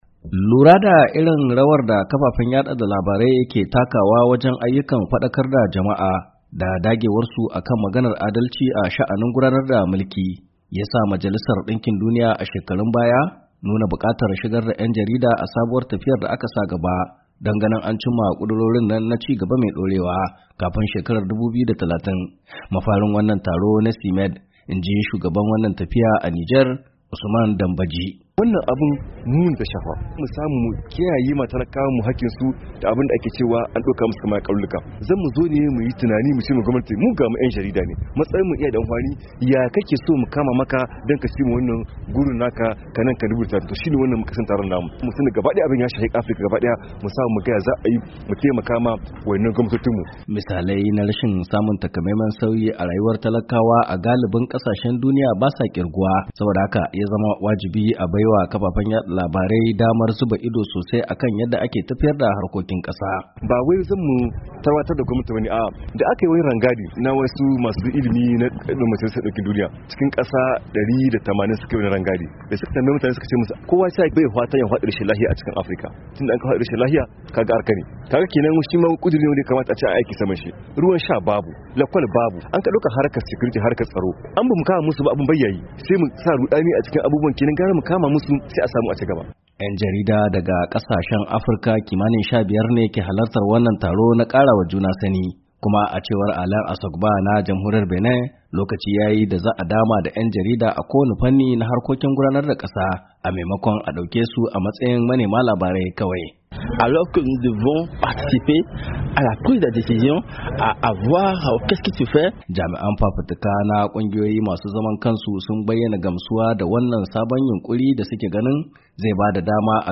Ga rahoto a sauti